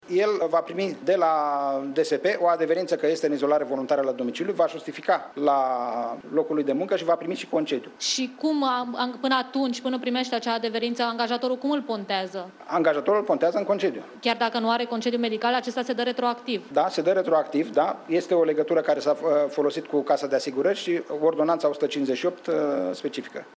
Nelu Tătaru, secretar de stat în ministerul Sănătății: